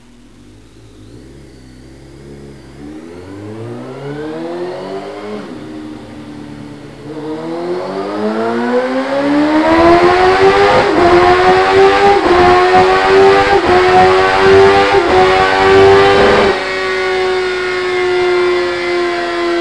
gsxr1000dyno_1_.wav